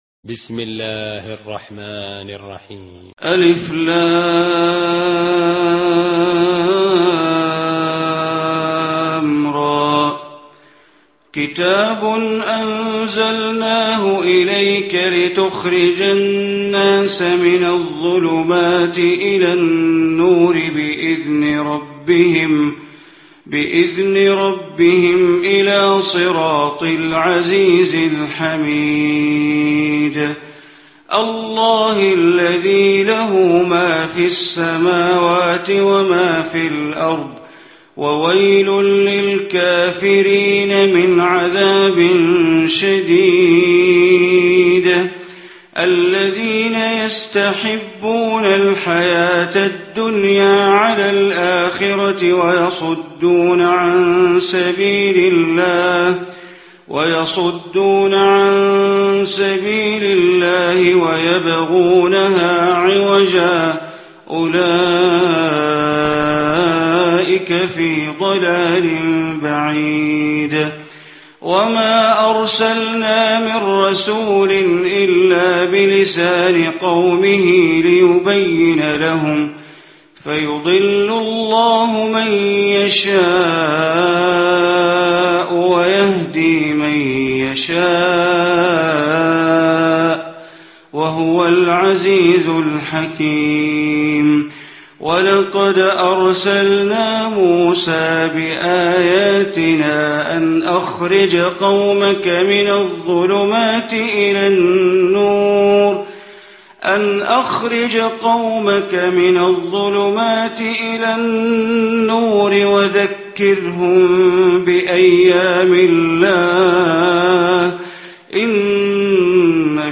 Surah Ibrahim Recitation by Sheikh Bandar Baleela
Surah Ibrahim, listen online mp3 tilawat / recitation in Arabic recited by Sheikh Bandar Baleela.